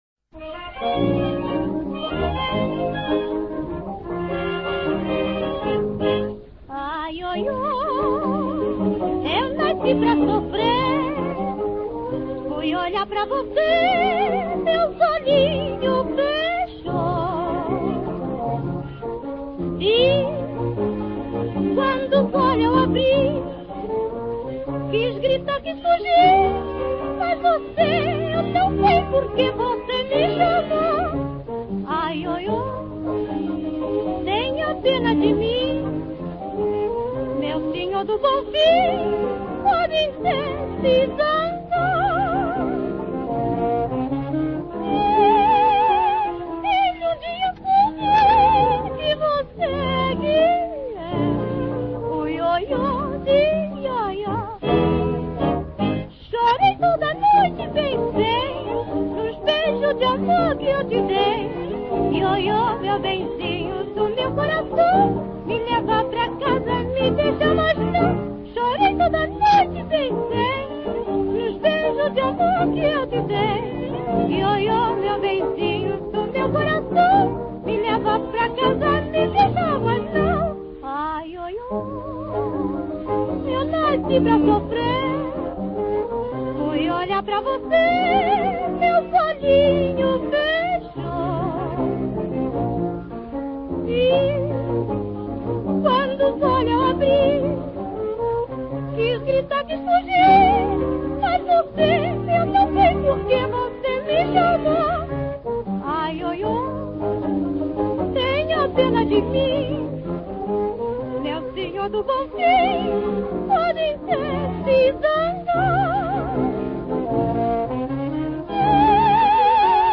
Bateria (Música)
Percussão (Música)